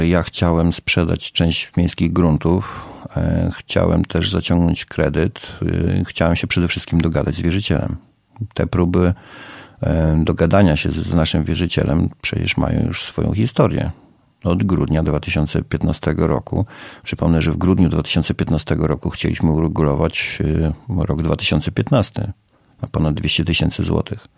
Szczegóły burmistrz przedstawił we wtorek (21.03) w Radiu 5.